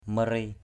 /mə-ri:/ (M. muri) 1.